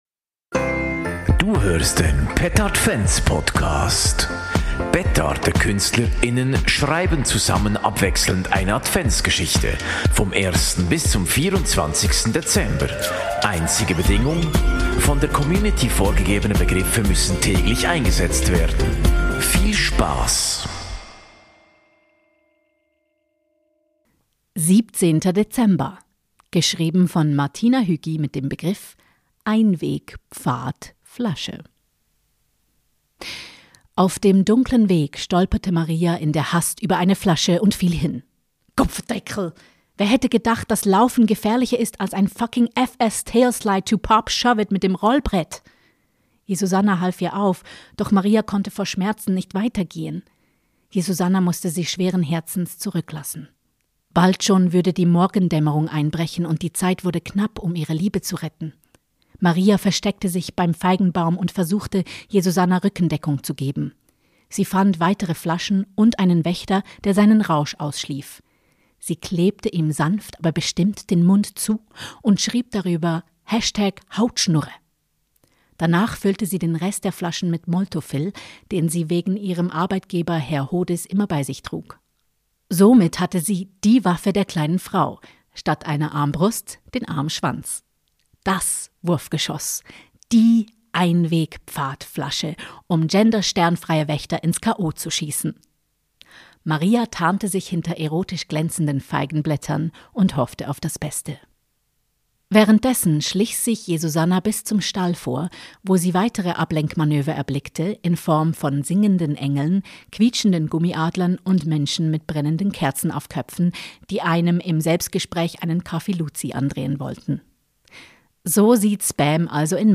Petardekünstler:innen schreiben abwechselnd eine Adventsgeschichte